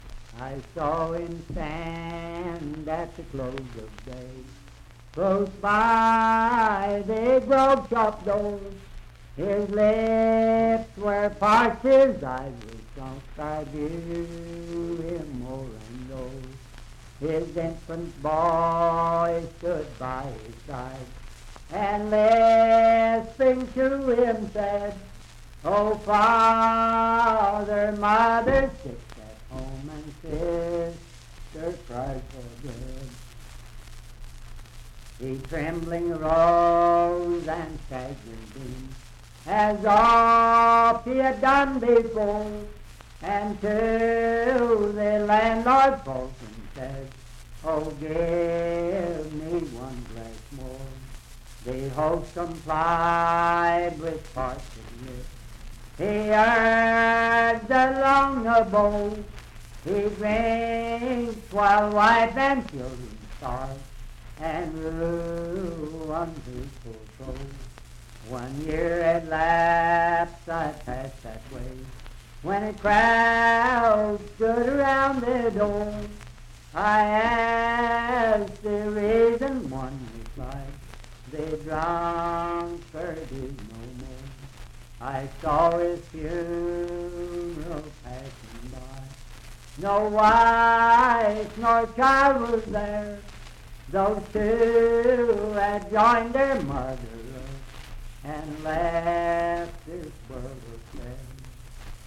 Unaccompanied vocal music
Voice (sung)
Wood County (W. Va.), Parkersburg (W. Va.)